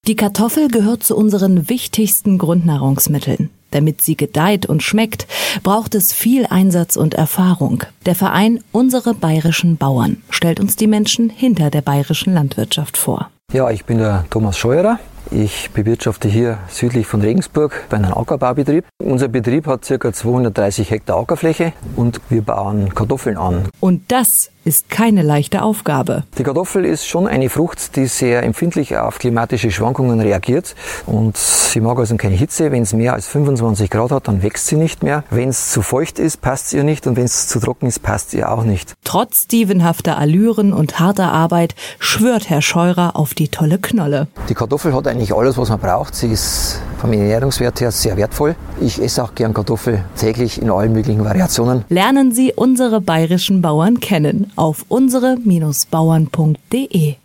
UBB_Radiospot_Kartoffelbauer.mp3